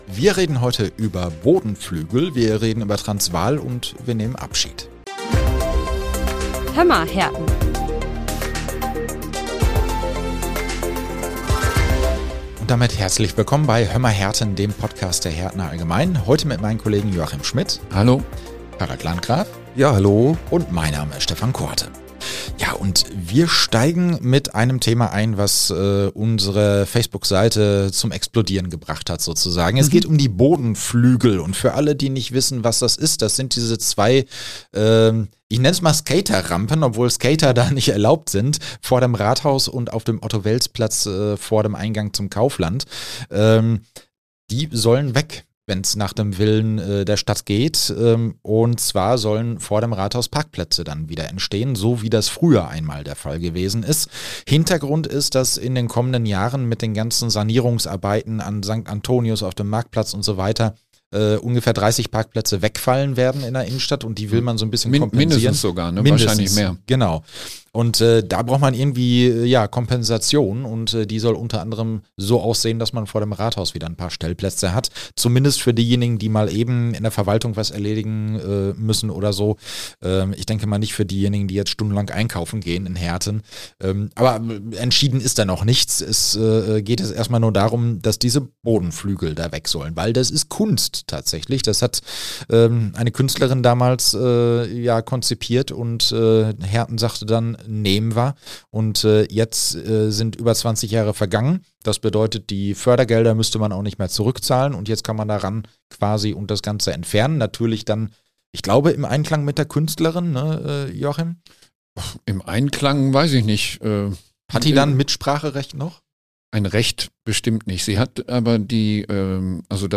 Sollen die Bodenflügel vorm Rathaus abgebaut werden? Woher kommt überhaupt der Name "Transvaal"? Und wir blicken auf das Leben von Ehrenbürger und Ex-Bürgermeister Willi Wessel zurück. Heute im Studio